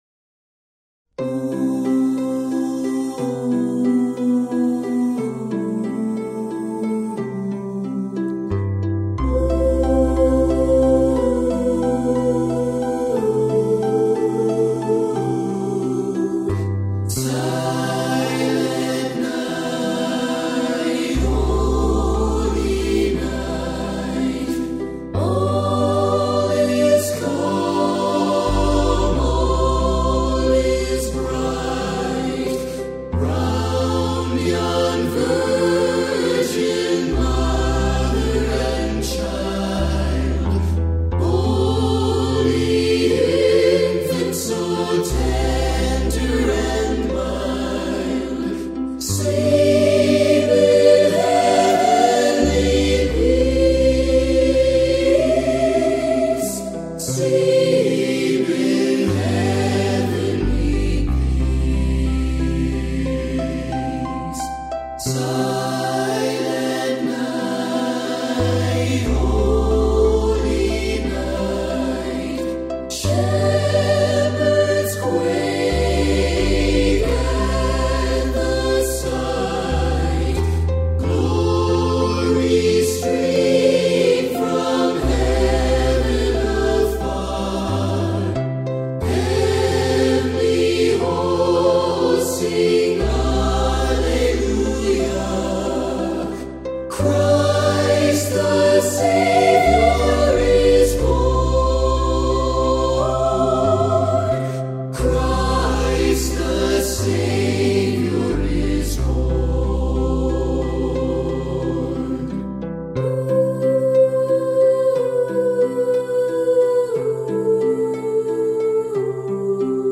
• Biểu diễn: Đồng ca